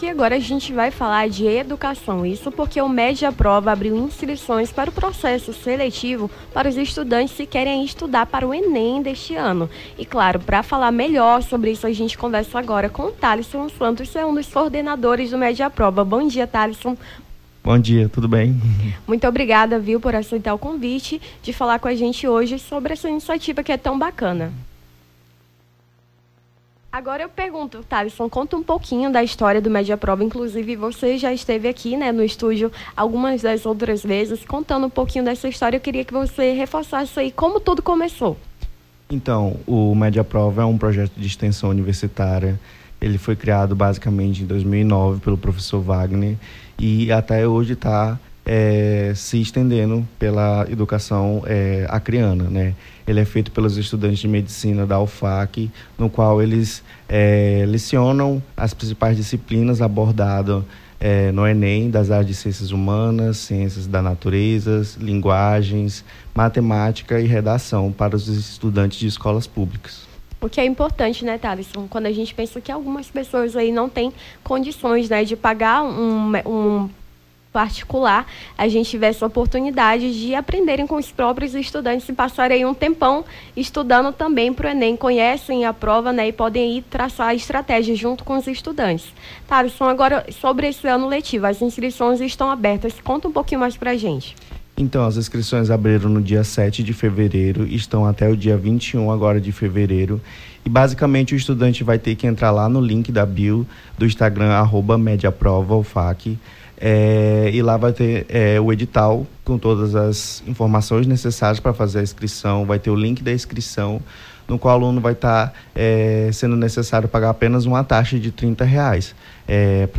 Nome do Artista - CENSURA - ENTREVISTA MED APROVA INSCRIÇÕES (19-02-24).mp3